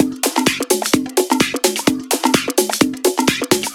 • spanish drum fills and rides tech.wav
spanish_drum_fills_and_rides_tech_Wc1.wav